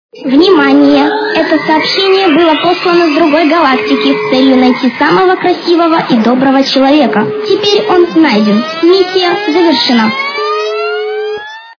» Звуки » Люди фразы » Сообщения с Галактики - Внимание это сообщение было послано с другой Галактики, с целю найти самого красивого и доброго человека, теперь он найден миссия завершена